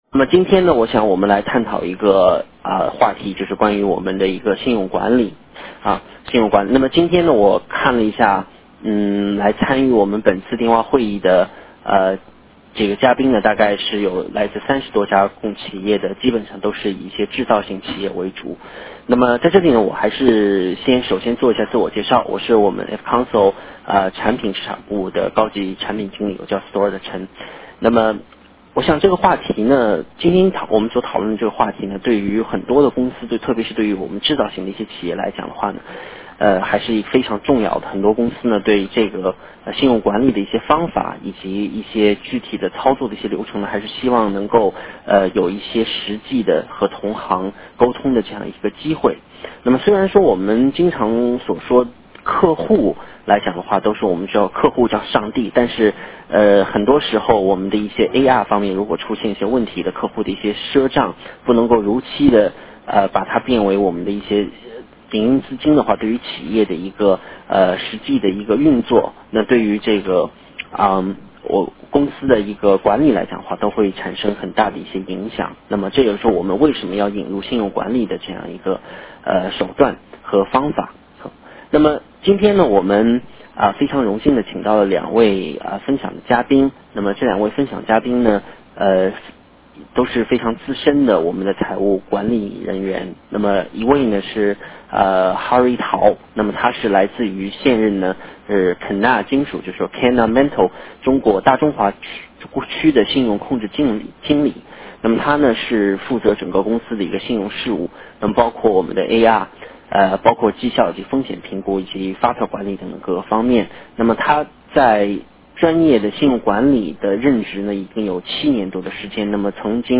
电话会议
形式：F-Council会员代表就跨国企业在信用管理的实际操作案例进行分享并进行问答等互动